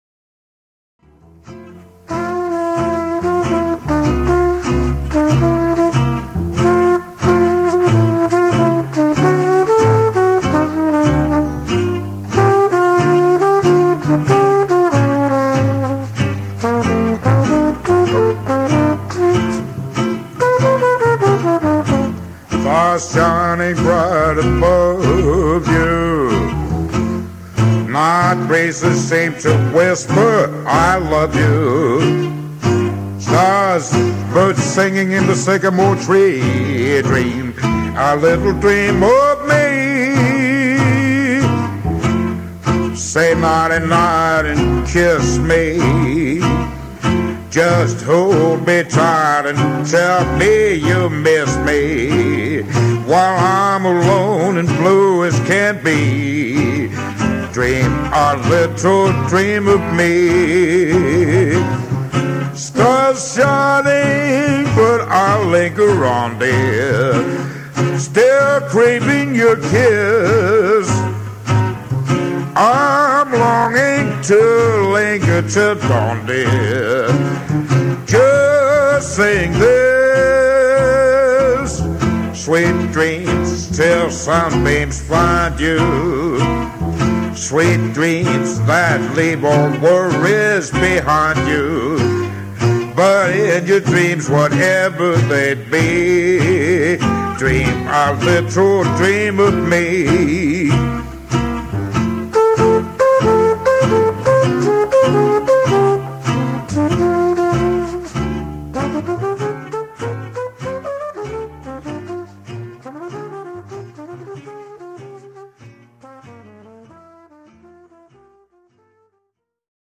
rytmeguitar & sang
cornet